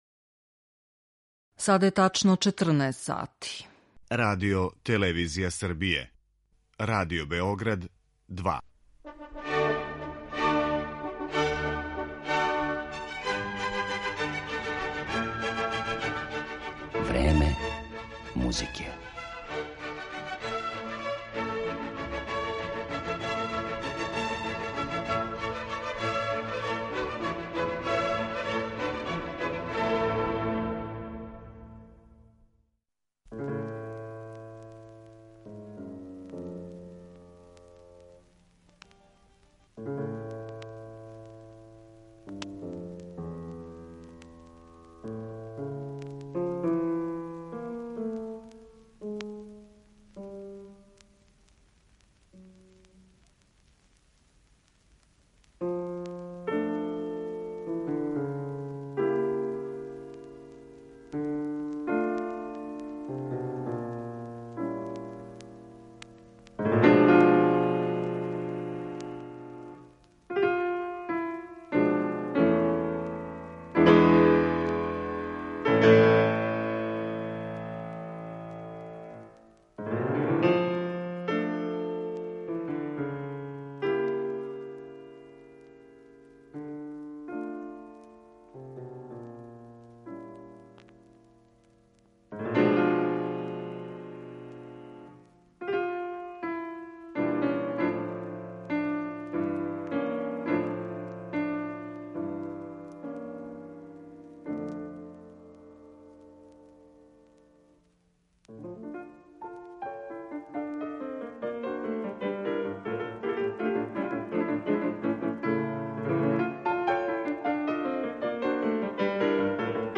Клавирски дуо
У сваком случају, у његовом опусу постоји више остварења за клавирски дуо и, ако судимо по комаду „Тмурна степа", Листу очигледно није било потребно да искористи све могућности које су у стању да понуде двоје пијаниста на једној клавијатури, већ је вероватно трагао за што необичнијим звучањима, посебно у овој минијатури где се смењују меланхоличне слике и ватрене играчке епизоде.